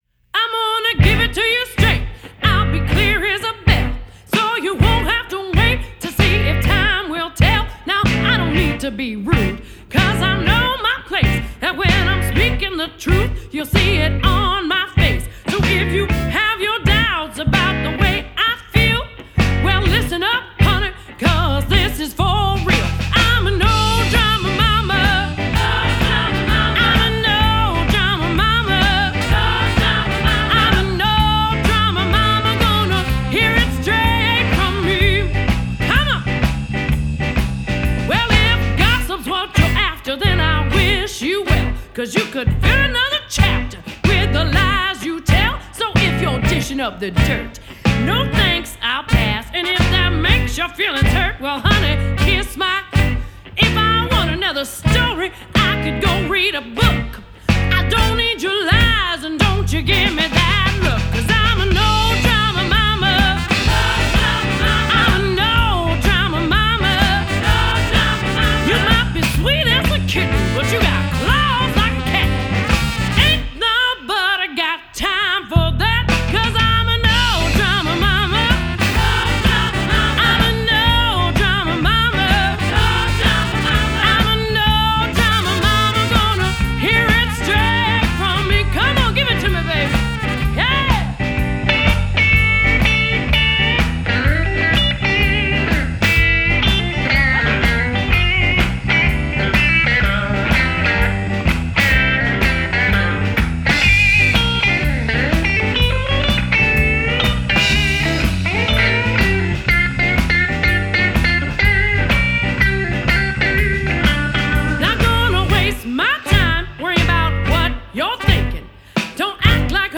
Twin Cities Famous Blues, Roots and Rock Band